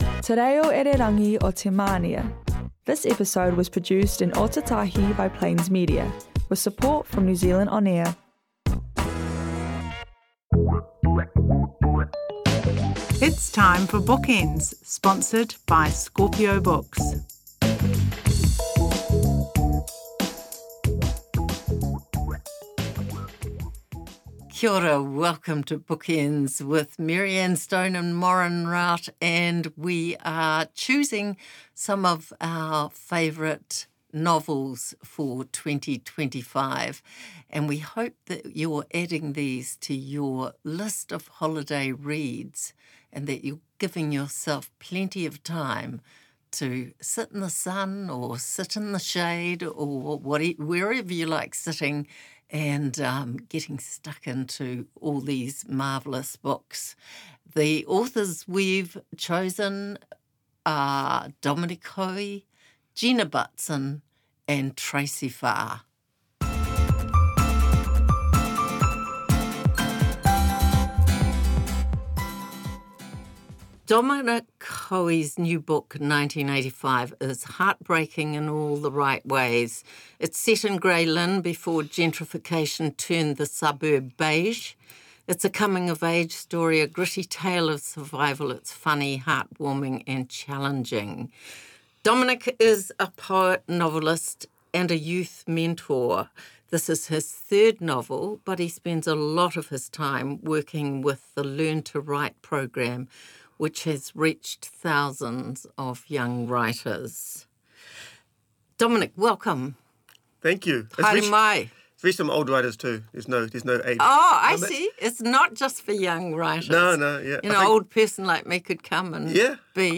Interviews with NZ writers and poets, visiting authors from around the world and news of local events